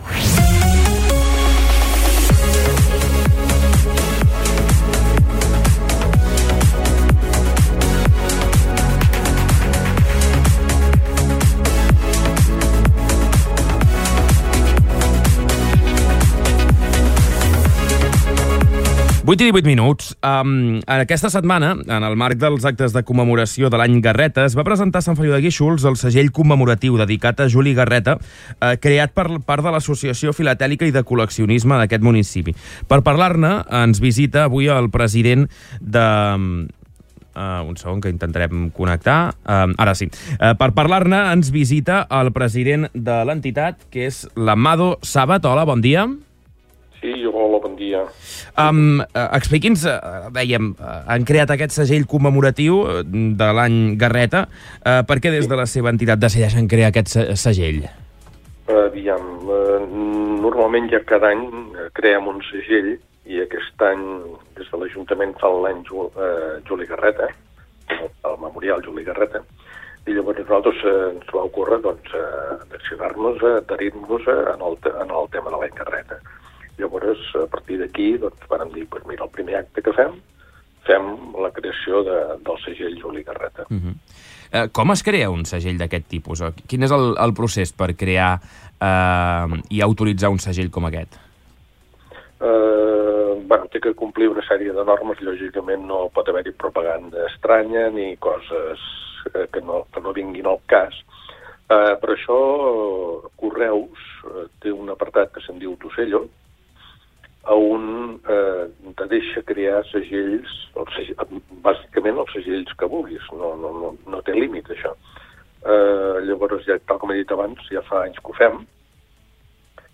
Els Festucs han passat aquest matí pel Supermatí d'estiu al Mercantil de Palafrugell i ens han tocat una cançó en directe!
Així mateix, hem parlat del concert que oferiran aquest divendres a la Festa Major de Palafrugell, del grup, dels components de la banda i tot plegat en una simpàtica conversa en un ambient molt distès.